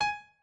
pianoadrib1_56.ogg